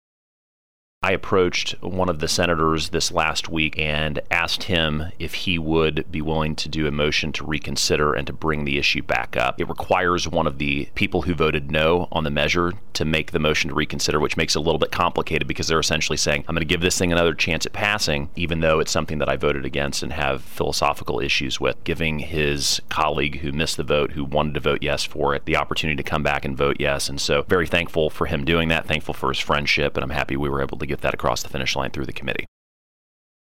1. Senator Luetkemeyer says Senate Bill 155, legislation that seeks to establish the Narcotics Control Act, will now go to the full Missouri Senate.